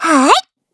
Taily-Vox_Attack1_kr.wav